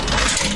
嗖嗖的大拳头
描述：我花了一段时间使用我以前的技术在我的其他打孔声音上创建这个声音只是为这一个添加了更多的嘶嘶声并使用了新的插件来添加到这个中的新声音。 应该用于巨大的打击Sfx
标签： 格斗 音响设计 音响-FX 脂肪 冲床 功夫
声道立体声